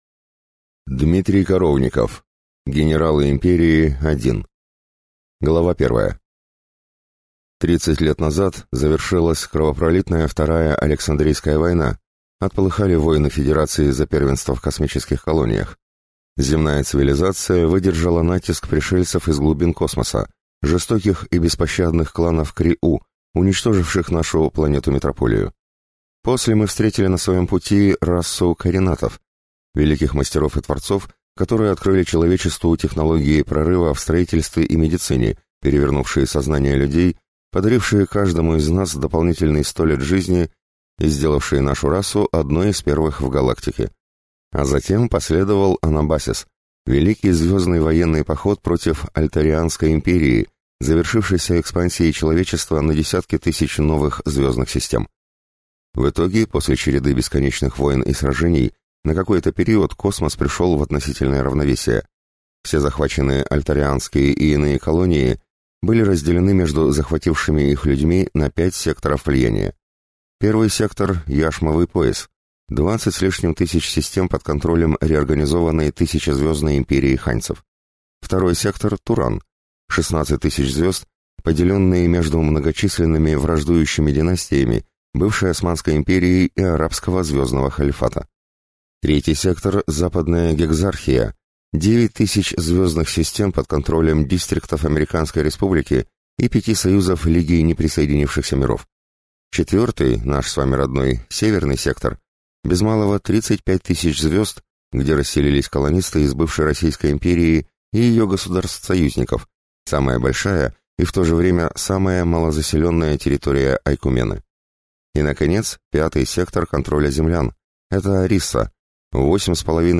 Aудиокнига Генерал Империи